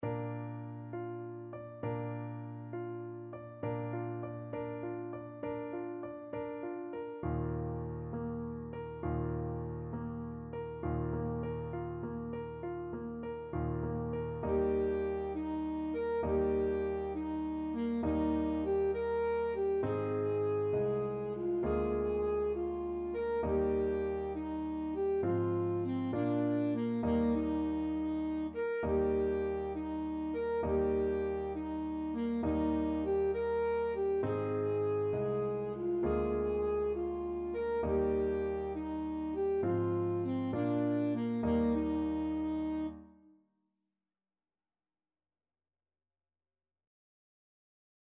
Alto Saxophone
Eb major (Sounding Pitch) C major (Alto Saxophone in Eb) (View more Eb major Music for Saxophone )
Allegretto
6/8 (View more 6/8 Music)
Bb4-Bb5
Classical (View more Classical Saxophone Music)